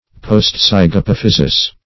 Search Result for " postzygapophysis" : The Collaborative International Dictionary of English v.0.48: Postzygapophysis \Post*zyg`a*poph"y*sis\, n.; pl.
postzygapophysis.mp3